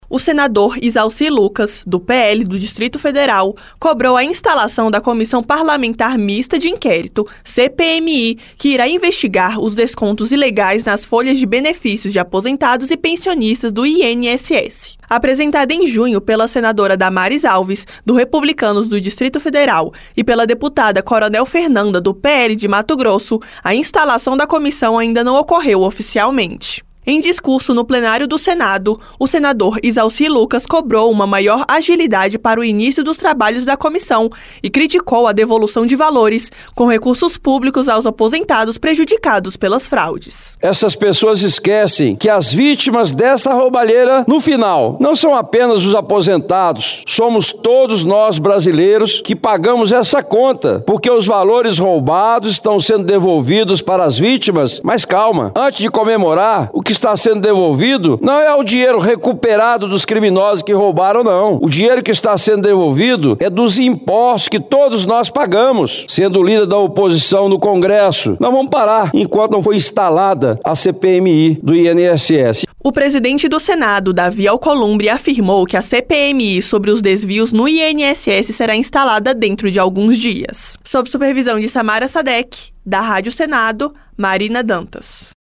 Em discurso no Senado, Izalci criticou a devolução de valores com recursos públicos.